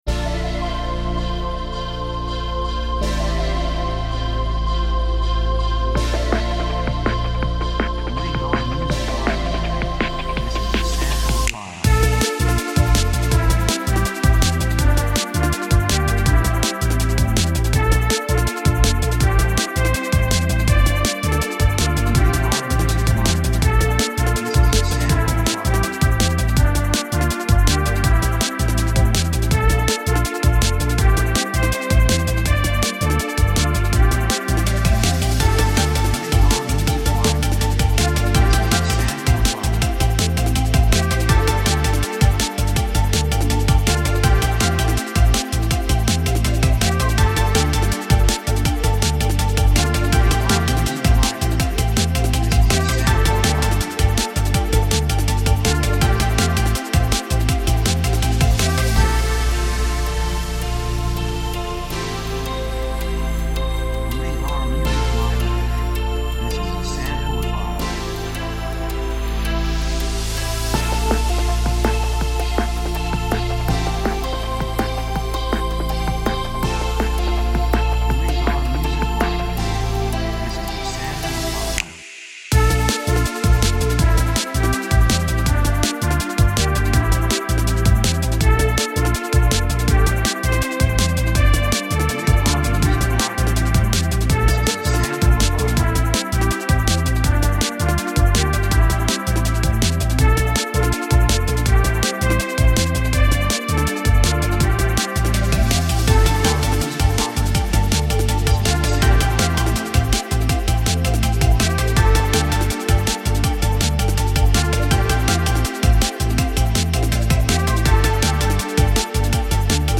2:32 163 プロモ, エレクトロニック